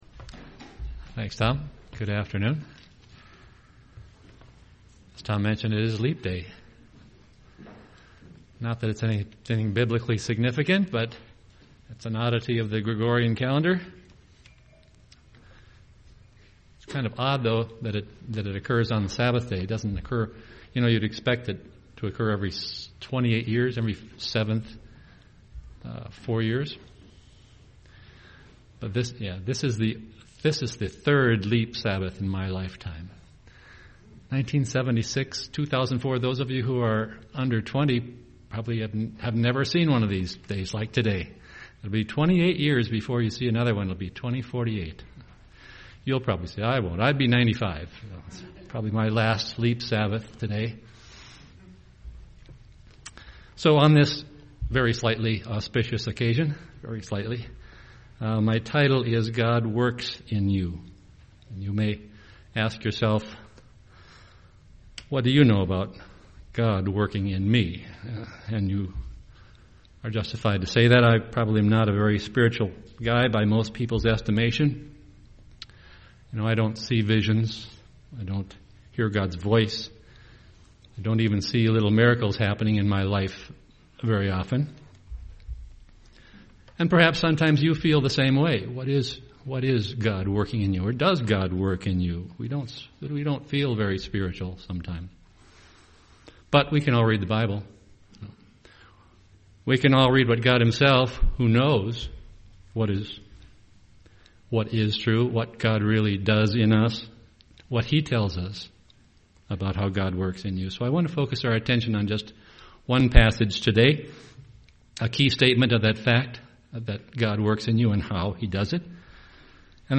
UCG Sermon calling God working in our lives Studying the bible?